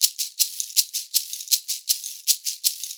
80 SHAK 12.wav